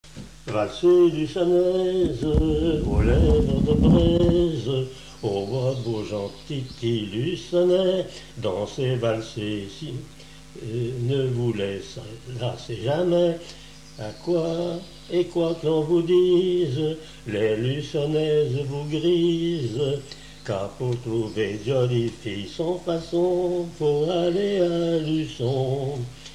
Genre strophique
Enquête Arexcpo en Vendée-Mairie Luçon
Catégorie Pièce musicale inédite